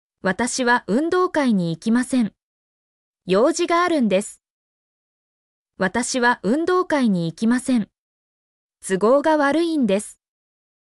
mp3-output-ttsfreedotcom-14_wbw7l5Dt.mp3